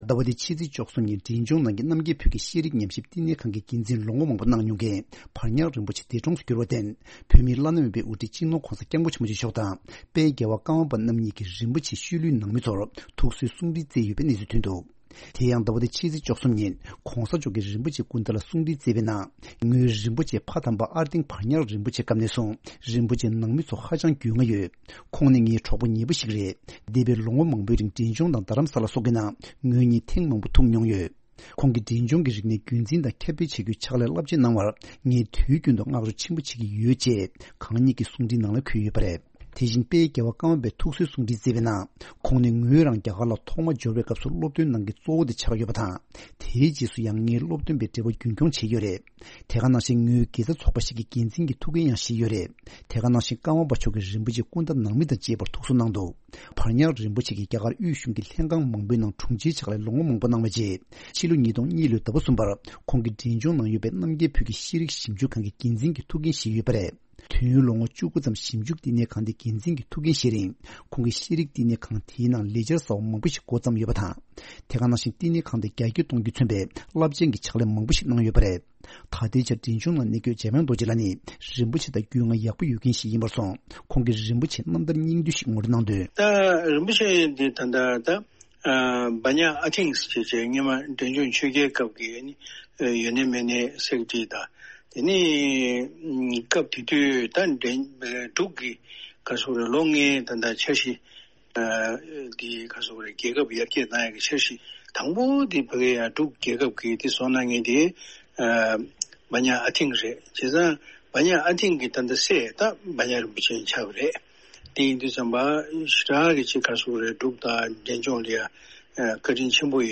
གནས་ཚུལ་སྙན་སྒྲོན་ཞུས་གནང་གི་རེད།